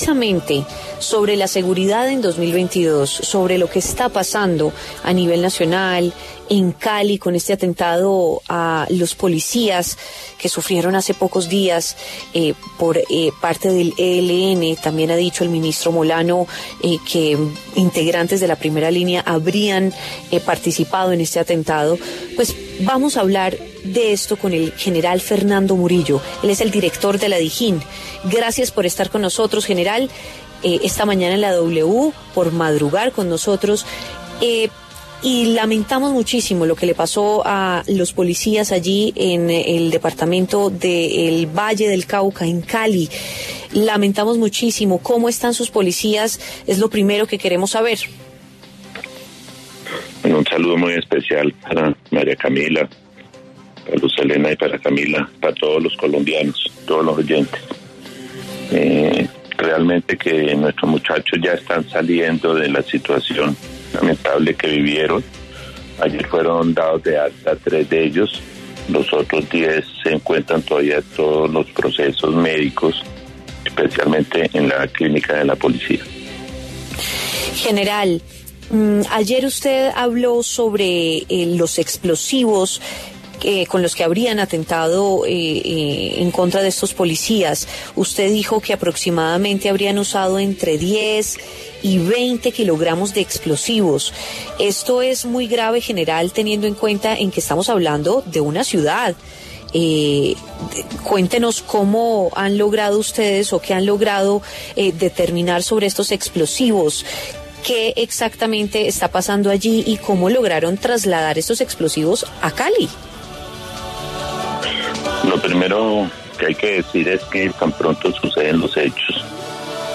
El General Fernando Murillo, director de la Dijín, habló en La W sobre el atentado en Cali que dejó 13 uniformados del Esmad heridos y que se adjudicó el ELN.